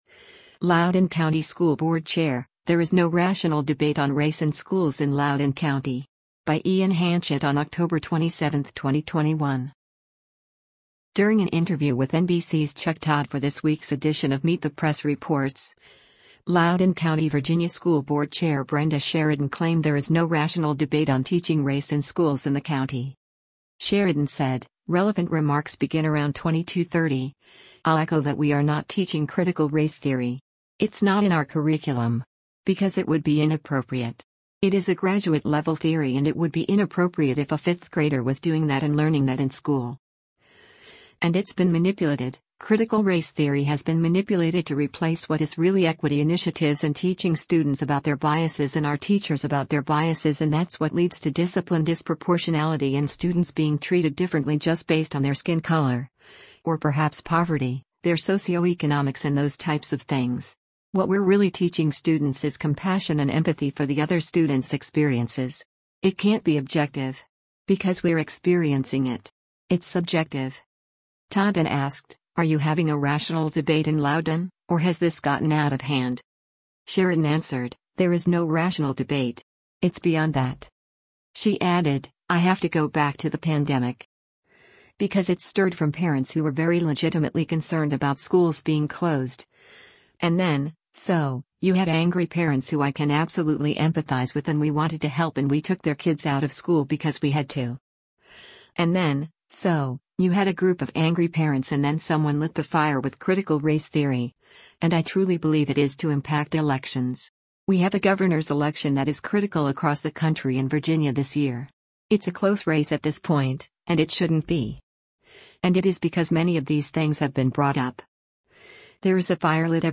During an interview with NBC’s Chuck Todd for this week’s edition of “Meet the Press Reports,” Loudoun County, VA School Board Chair Brenda Sheridan claimed there “is no rational debate” on teaching race in schools in the county.